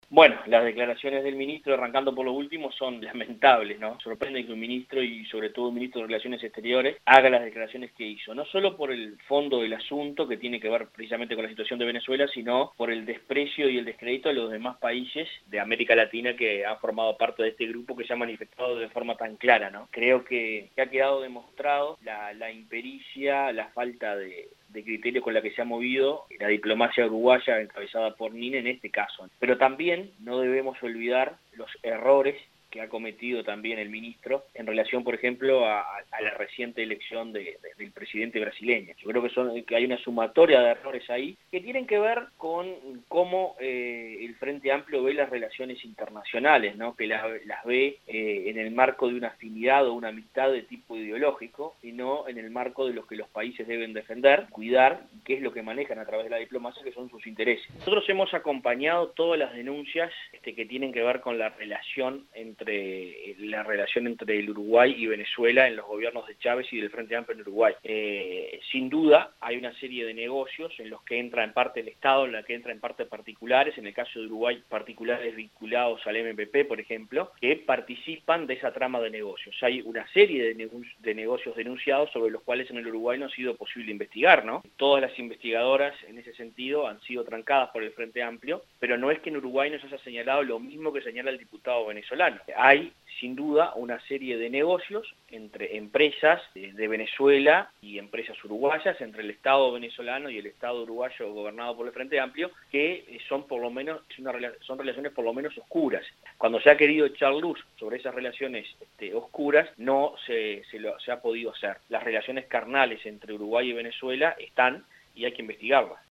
El diputado Adrían Peña dijo a 970 Noticias que las declaraciones del Ministro fueron lamentables, agregó que las relaciones entre Uruguay y Venezuela deben investigarse.